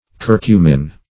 Curcumin \Cur"cu*min\ (-m?n), n. (Chem.)